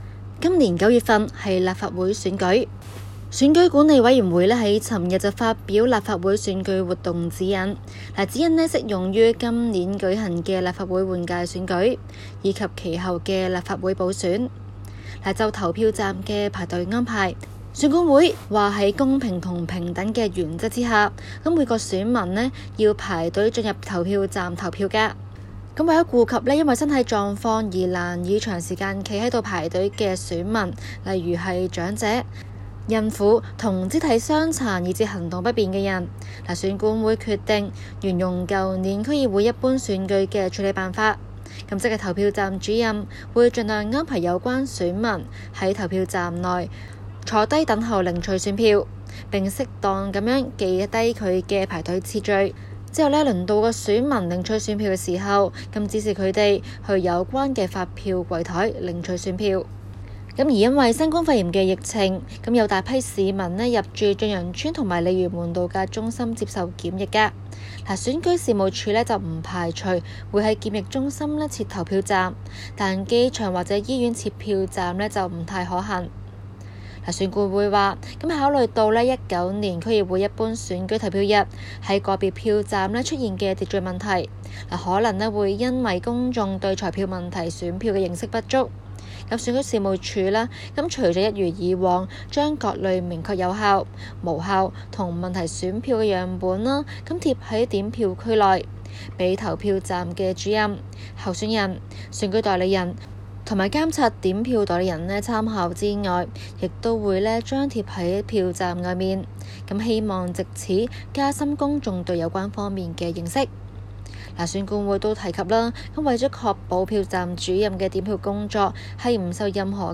今期 【中港快訊 】環節報道選舉管理委員會（選管會）發出指引針對長者輪候安排及點算秩序等事宜。